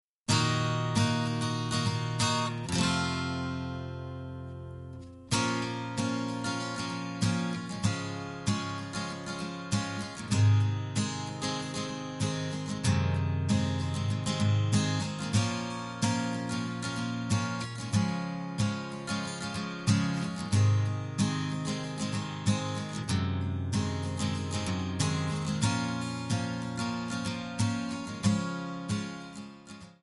D
Backing track Karaoke
Pop, Rock, 1990s